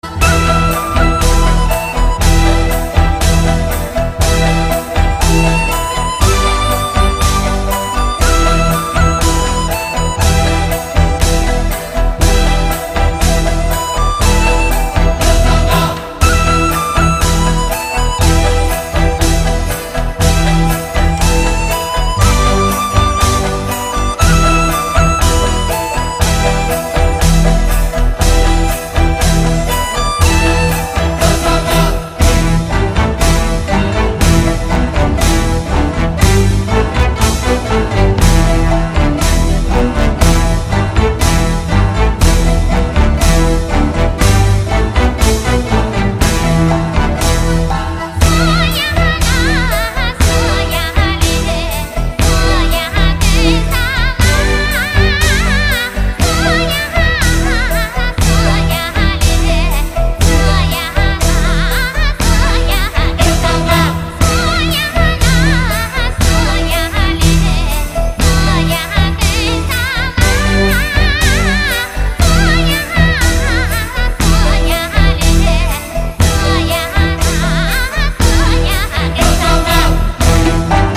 录音的整体上显得非常平衡，是近年来难得的一张录制优秀的音乐专辑。